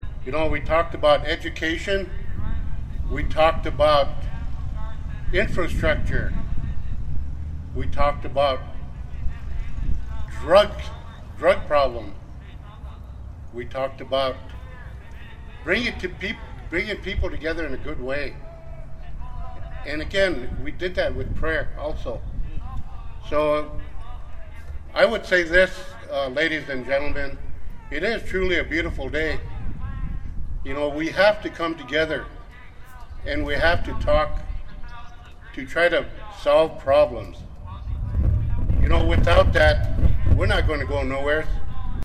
Singing, dancing, praying, praising and protesting all took place on the lawn of the South Dakota State Capitol in Pierre today (May 20) during the Governor’s Round Dance event.
Standing Rock Sioux Tribal Chairman Mike Faith shared a message about problem solving.